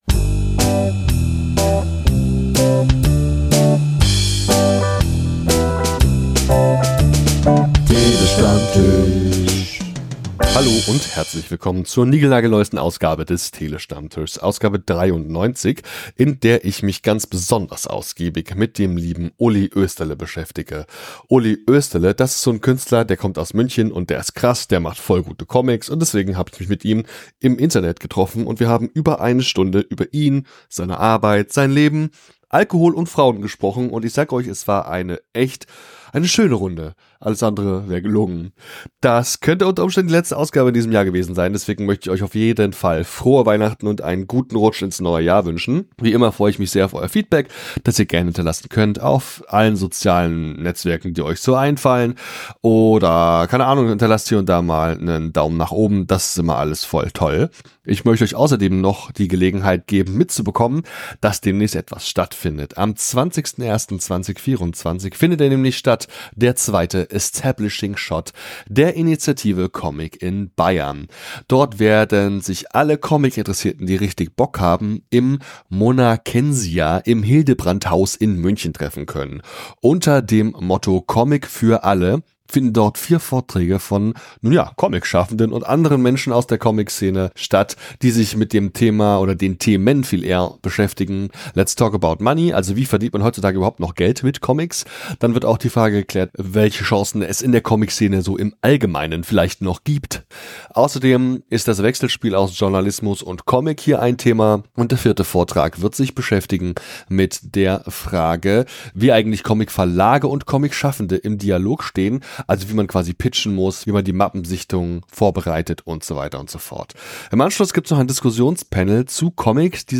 Short Crowd Cheer 2.flac